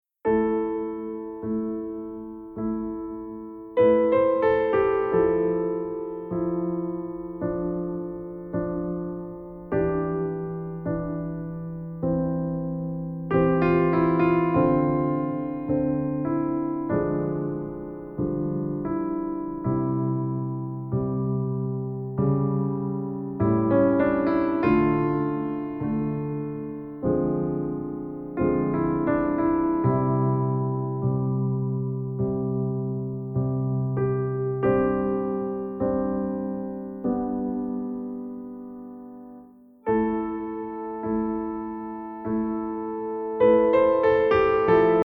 Mp3 Instrumental Song Download